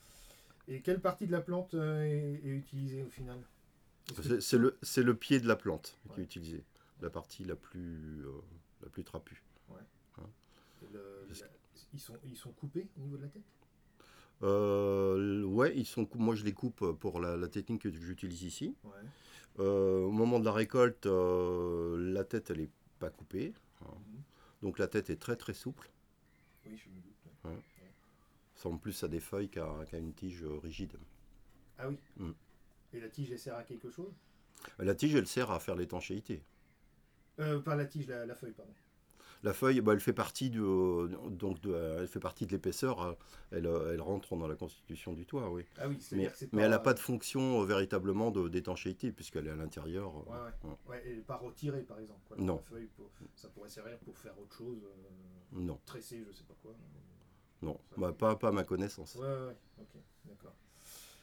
Témoignage sur la couverture végétale